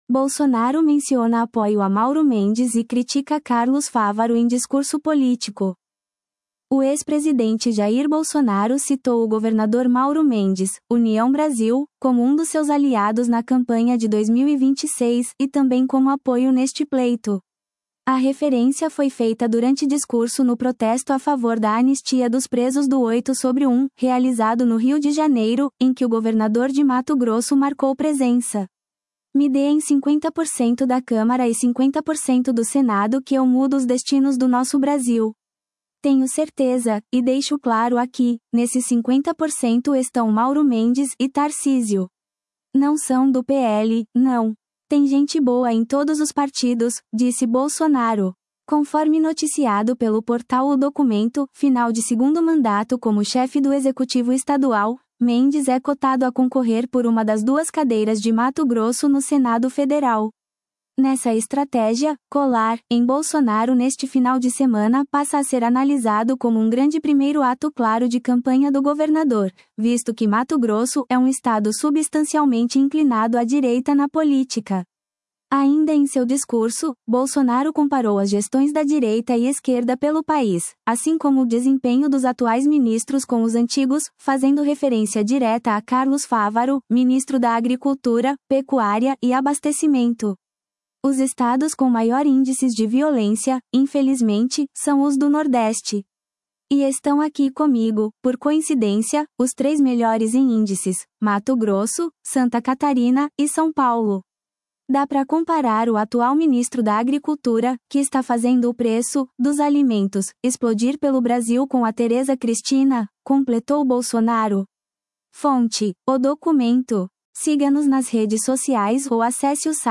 Bolsonaro menciona apoio a Mauro Mendes e critica Carlos Fávaro em discurso político
A referência foi feita durante discurso no protesto a favor da anistia dos presos do 8/1, realizado no Rio de Janeiro, em que o governador de Mato Grosso marcou presença.